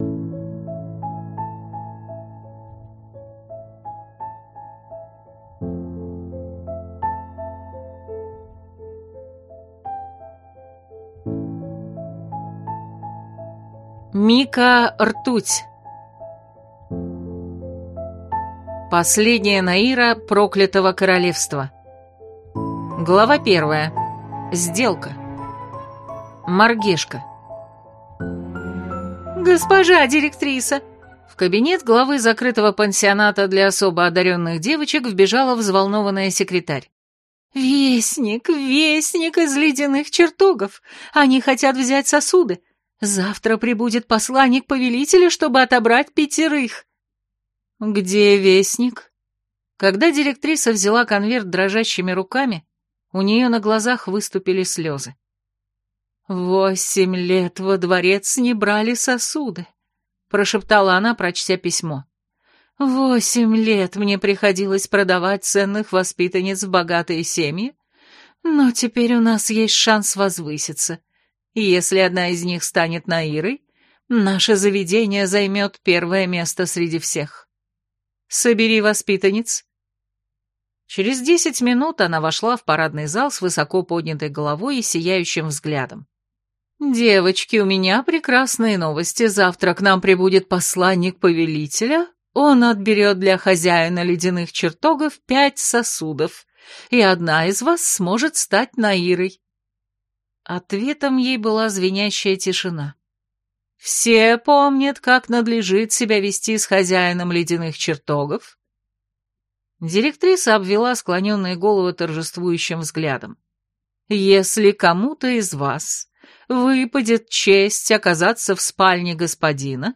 Аудиокнига Последняя наира проклятого королевства | Библиотека аудиокниг
Прослушать и бесплатно скачать фрагмент аудиокниги